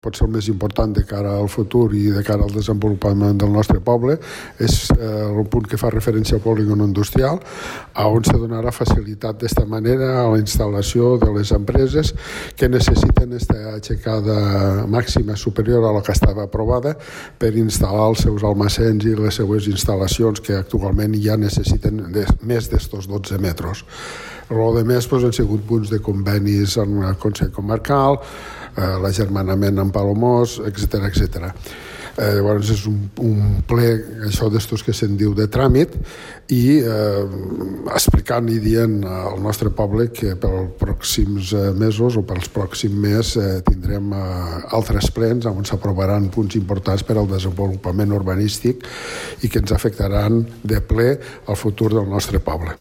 Antonio Espuny és el primer tinent d’alcalde, i portaveu de Compromís per la Cala: